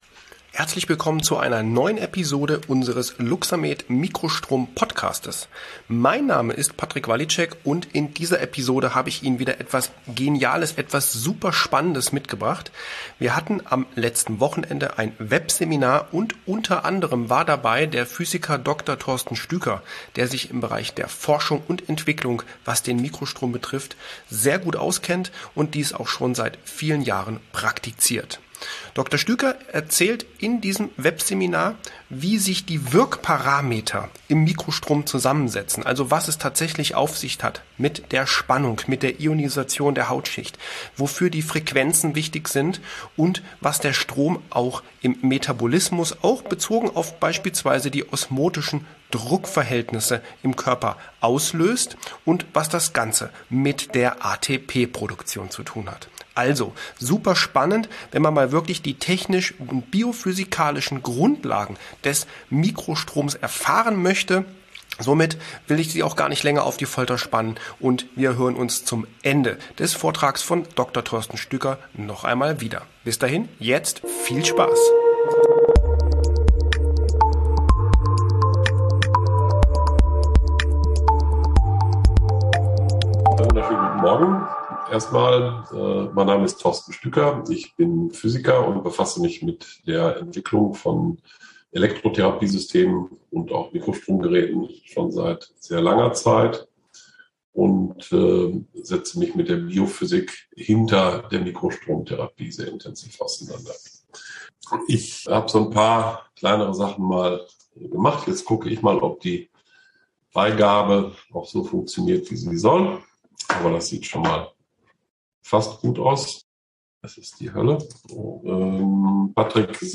Diese und noch weitere Fragen haben wir in einem Webseminar mit Anwendern besprochen.